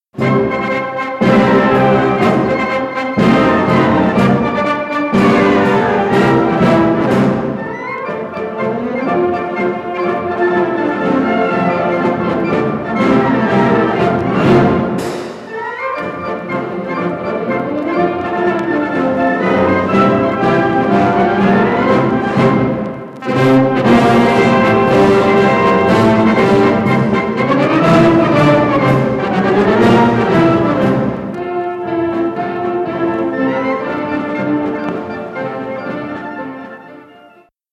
Sintonia del programa